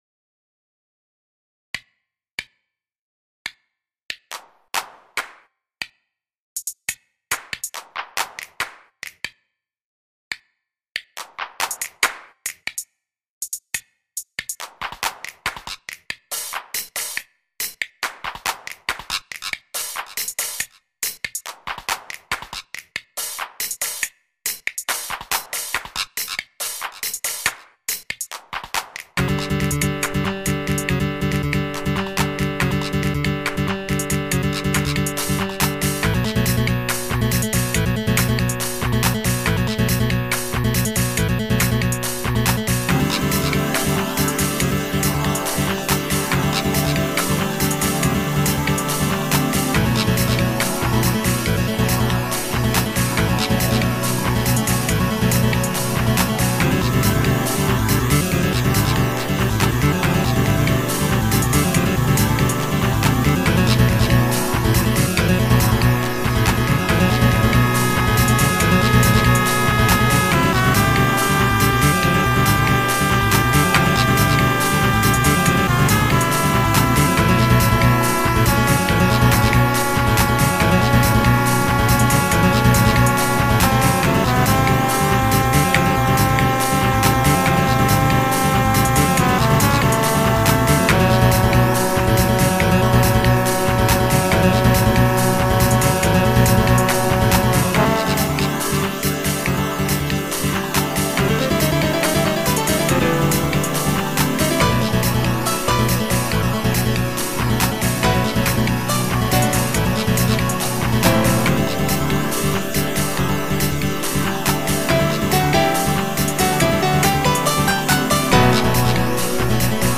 These were all written in music making software.
Nice guitar solo in there